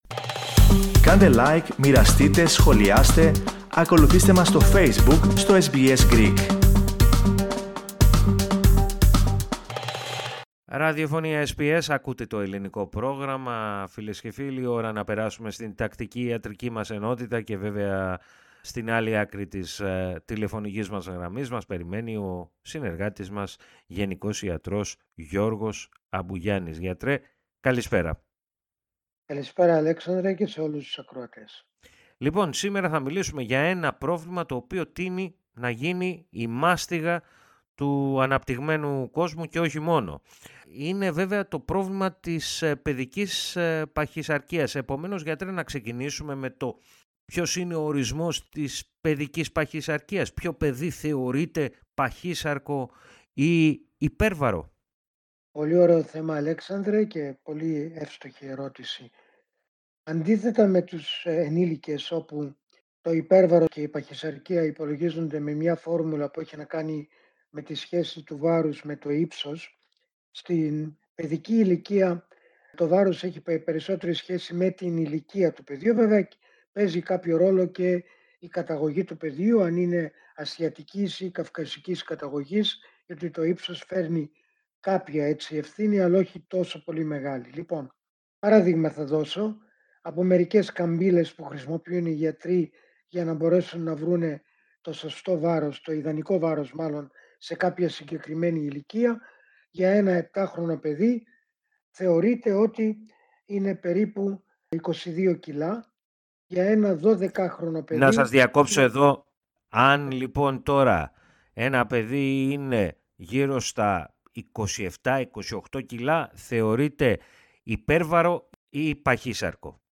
Πώς, όμως, αντιμετωπίζεται η παιδική παχυσαρκία, και ποιοι μπορεί να είναι οι κοινωνικοί παράγοντες που τη δημιουργούν και τη συντηρούν; Ακούστε ολόκληρη τη συνέντευξη, πληκτρολογώντας στο ειδικό σύμβολο, πάνω από την κεντρική φωτογραφία.